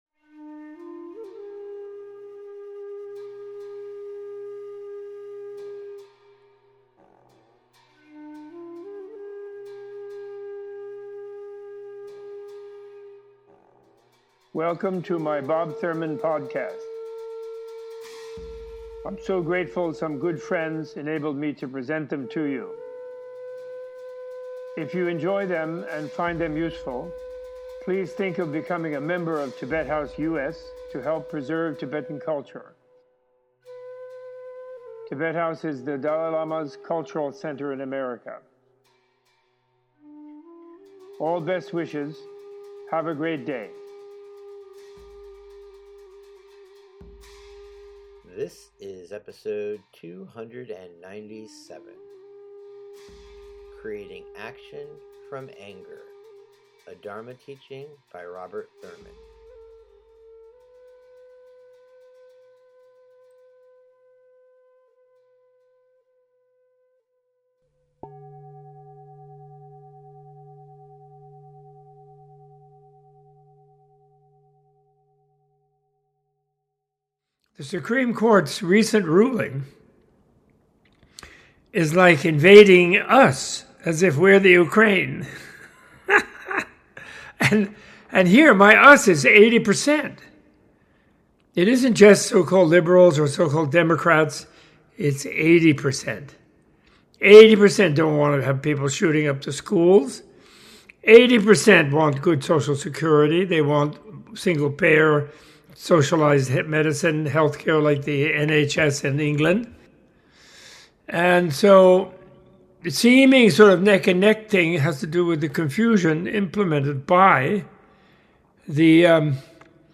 In this episode Robert A.F. Thurman discusses the ongoing effects of the Trump presidency on American civil rights, constitutional protections, health care, women’s reproductive access, and sensible gun laws, as well as the Buddha’s revolutionary redefinition of Dharma. Opening with a discussion of the writings and work of Patrick Olivelle, Thurman gives a traditional Buddhist Dharma teaching on the interconnected blissful nature of reality, exploring the nurturing and transformative qualities of seeing the world as it actually is and the potential of all beings to end suffering through wisdom and compassion as the historical Buddha did.